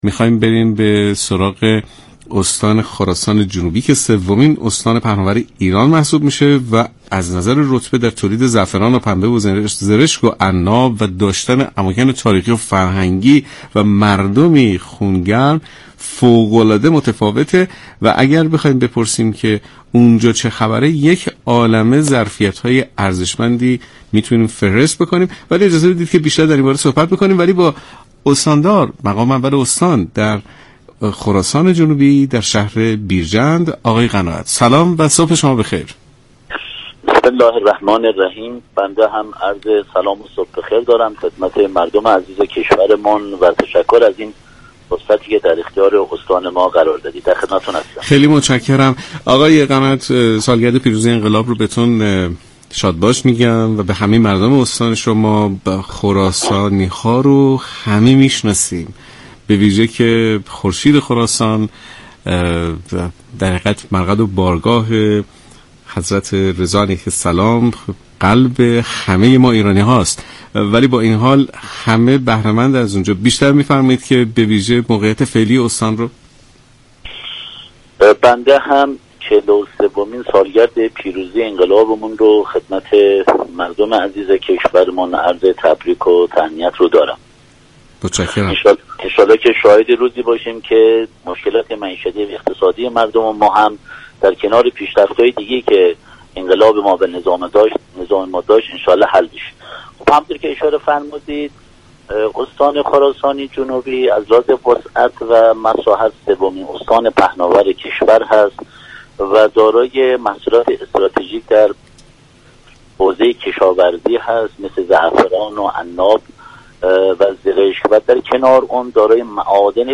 به گزارش شبكه رادیویی ایران، «جواد قناعت» استاندار استان خراسان جنوبی، در برنامه «صبح انقلاب» به طرح در حال افتتاح در این استان اشاره كرد و گفت: استان خراسان جنوبی امسال در صدد افزایش واحدهای فرآوری محصولات در حوزه معدن است، این اقدام سبب ایجاد فرصت های شغلی زیادی خواهد شد.